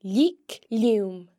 The slender L sound is made by pressing your tongue against the roof of your mouth (palate) while pronouncing the letter, and occurs when the L is before or after an e or i. The slender L can be heard in leugh (read):